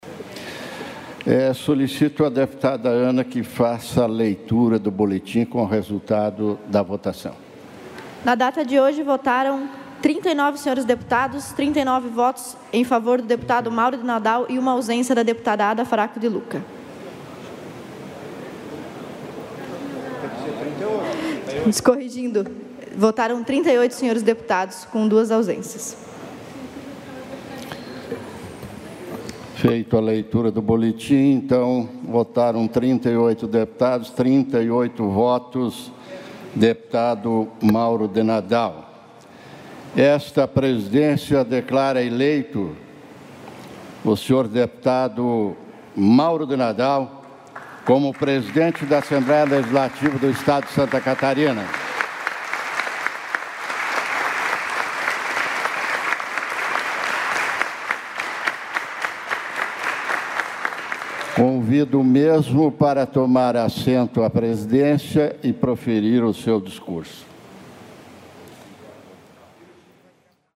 Confira os pronunciamentos dos deputados durante a sessão preparatória desta segunda-feira (1º) para a eleição do presidente e da Mesa Diretora da Assembleia Legislativa para o biênio 2021-2023.
- Discurso do presidente eleito, Mauro de Nadal (MDB);